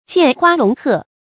檻花籠鶴 注音： ㄐㄧㄢˋ ㄏㄨㄚ ㄌㄨㄙˊ ㄏㄜˋ 讀音讀法： 意思解釋： 柵欄中的花、籠中的鶴。比喻受到約束的人或物 出處典故： 清 龔自珍《好事近 行篋中有像一幅以詞為贊》：「倘然生小在侯家，天意轉孤負。